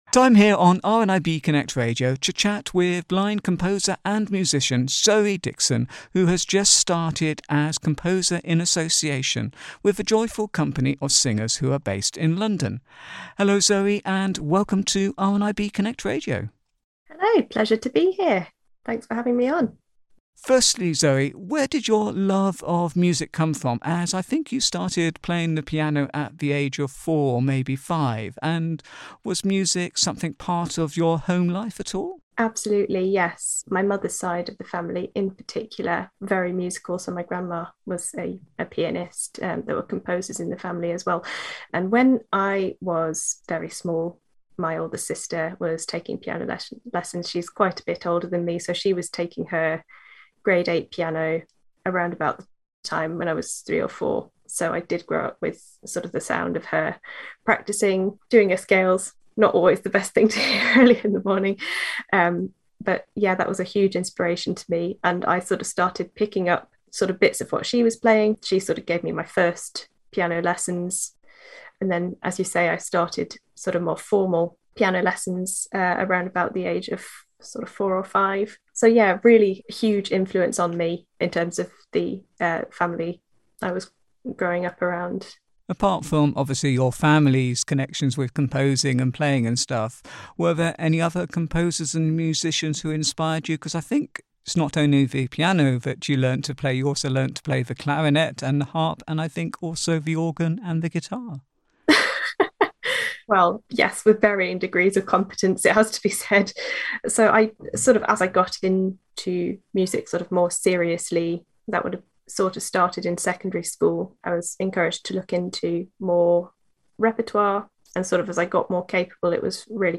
Blind Composer and Musician Profile Interview